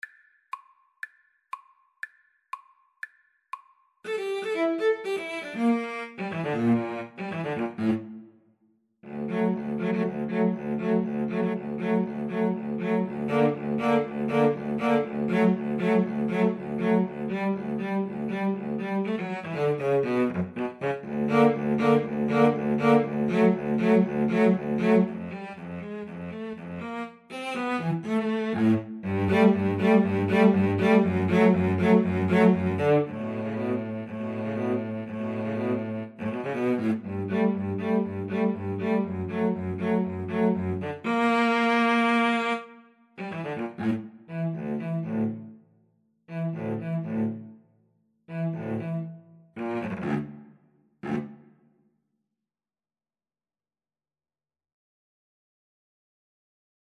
Allegro giusto (View more music marked Allegro)
Classical (View more Classical Violin-Cello Duet Music)